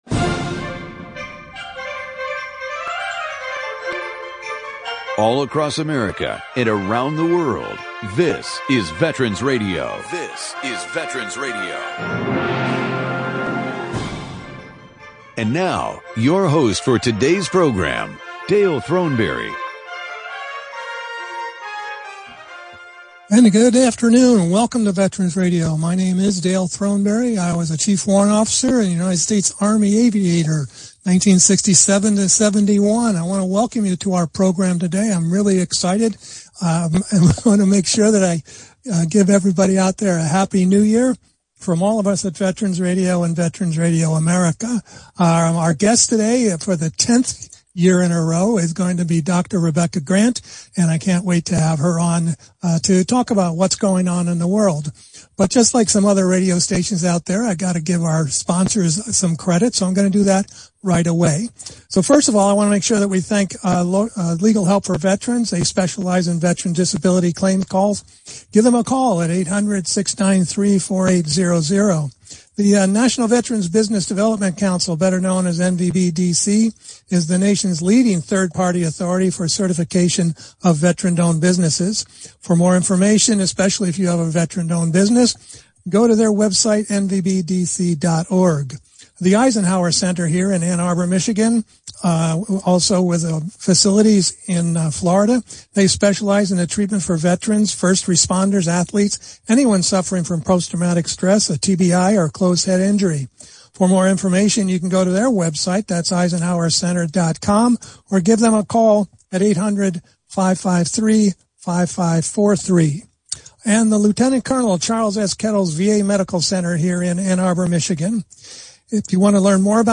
This week’s one hour radio broadcast is a State of the World review